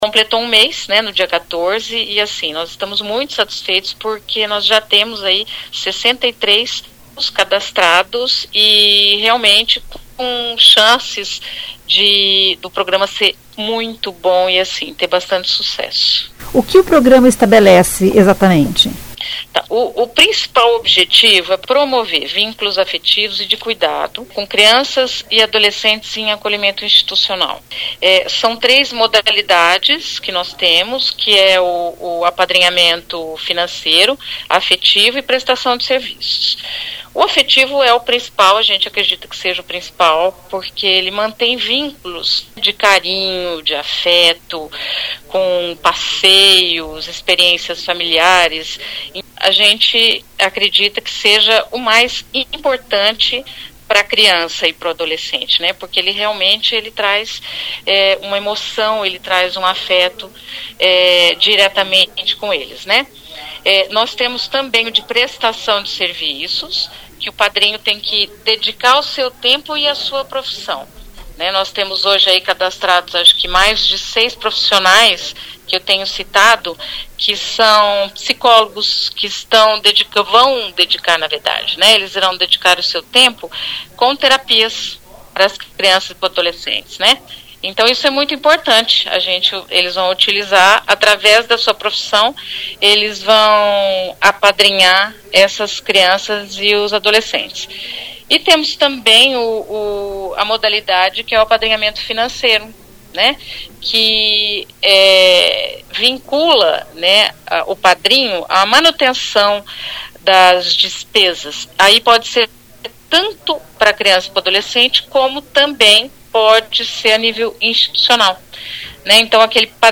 A secretária da Criança e do Adolescente, Carmem Inocente, diz que dos 63 inscritos no programa, a maioria quer prestar acompanhamento afetivo. Os inscritos em modalidades onde há contato direto com as crianças serão capacitados e em 2026 poderão iniciar o atendimento. Ouça o que diz a secretária: